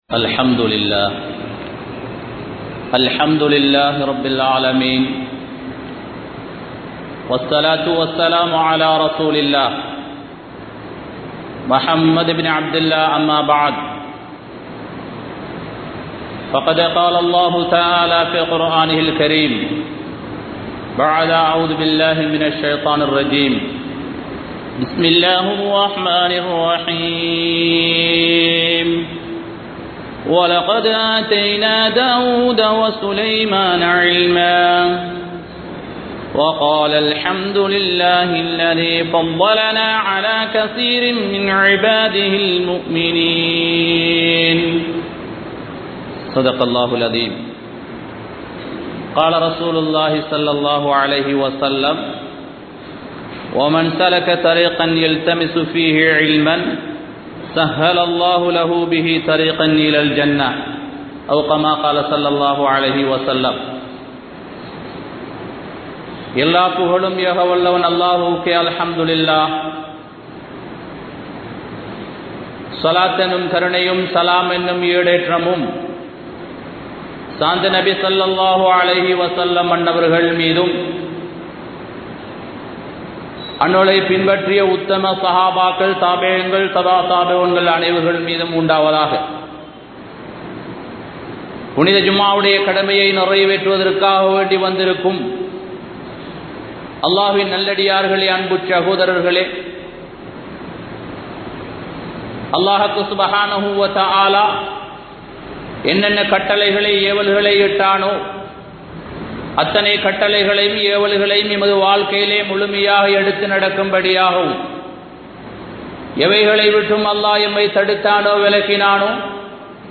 Ilmai Katpathan Avasiyam (இல்மை கற்பதன் அவசியம்) | Audio Bayans | All Ceylon Muslim Youth Community | Addalaichenai
Mallawapitiya Jumua Masjidh